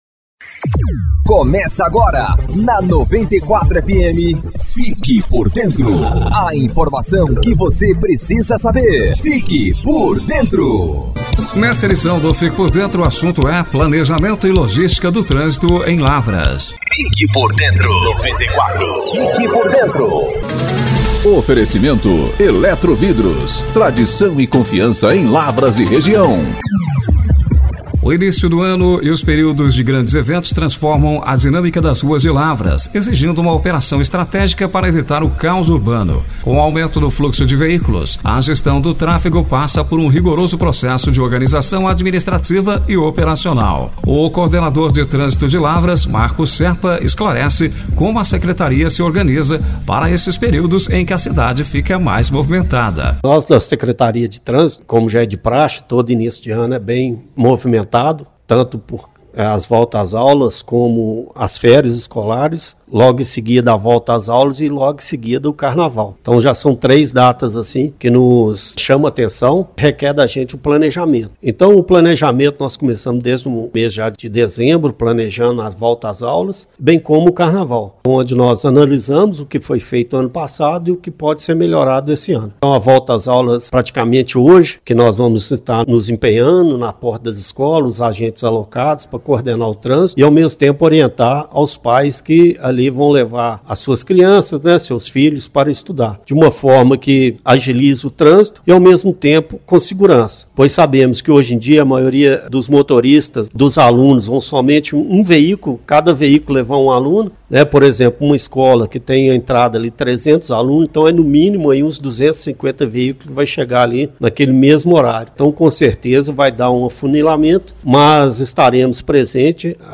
Para discutir estratégias que melhorem a fluidez e a segurança viária, o programa recebeu especialistas e autoridades para analisar o cenário atual da mobilidade urbana no município. O foco principal está na readequação de vias de grande movimento e na implementação de sinalizações que priorizem não apenas o fluxo de carros, mas também a travessia segura de pedestres.